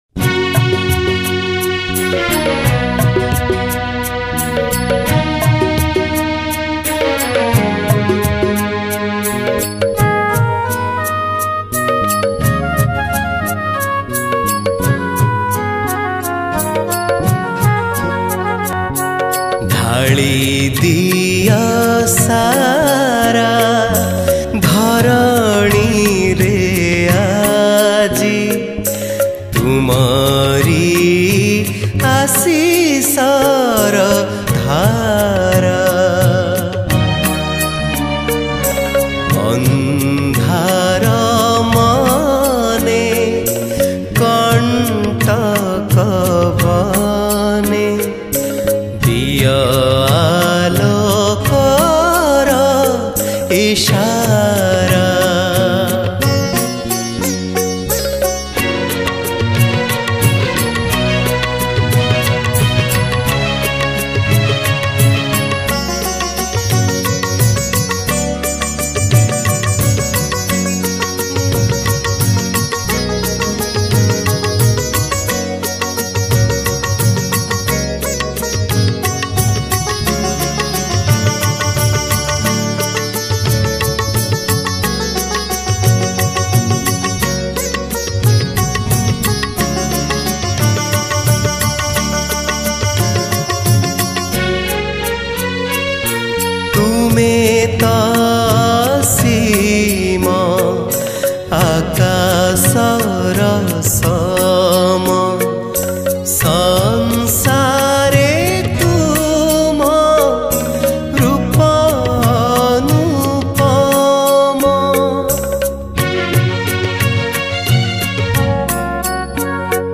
Category: Odia Bhakti Hits Songs